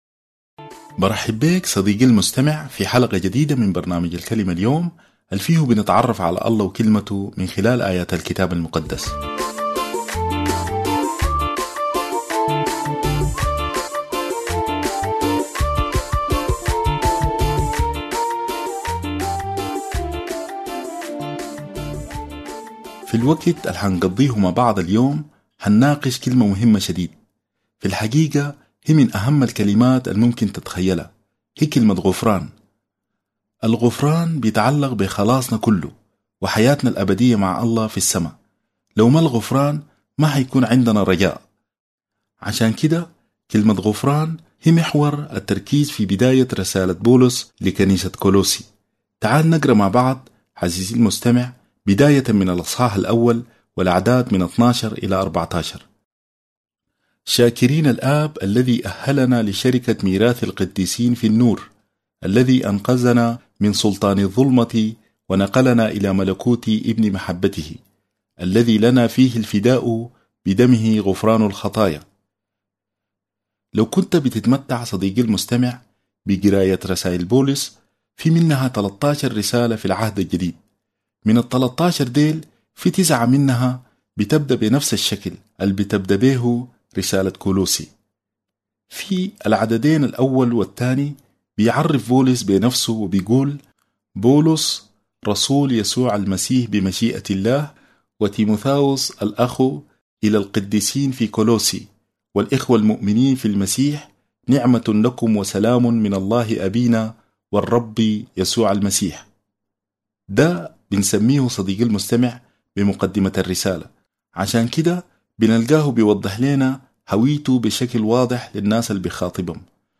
الكلمة اليوم باللهجة السودانية